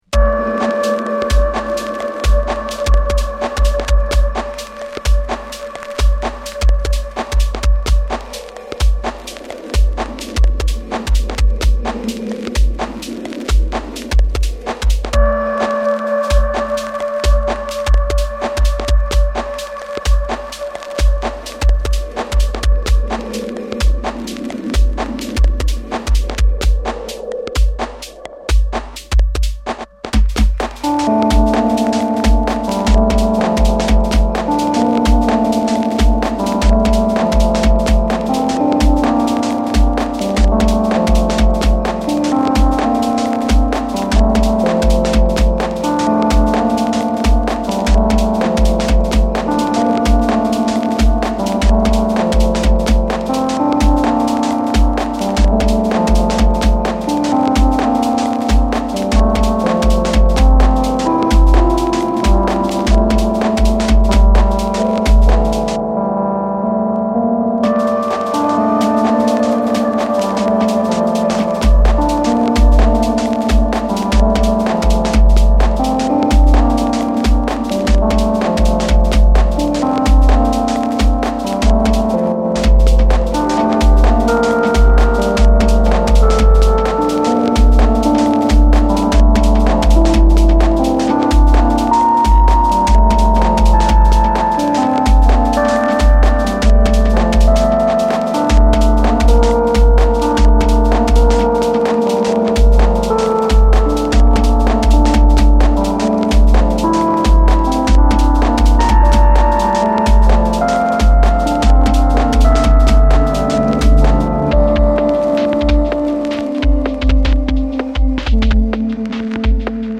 テクノミニマル
美しいアンビエンスをも解釈できる